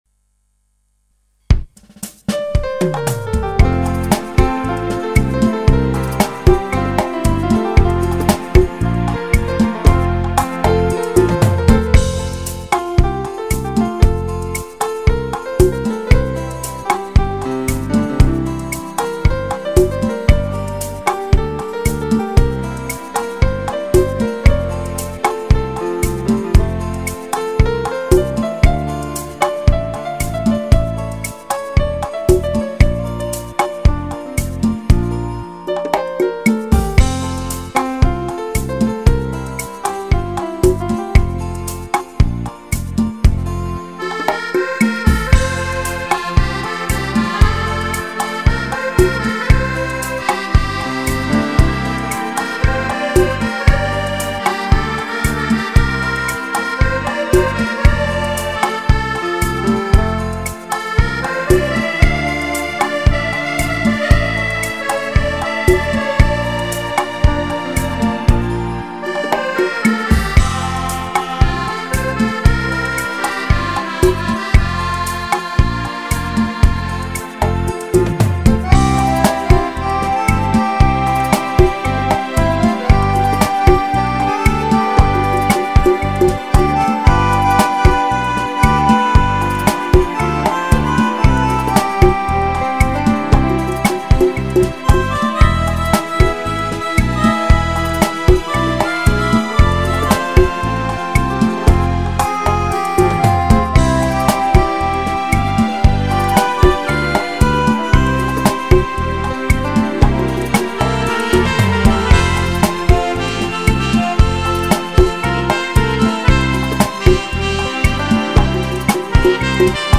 Canzoni e musiche da ballo
rumba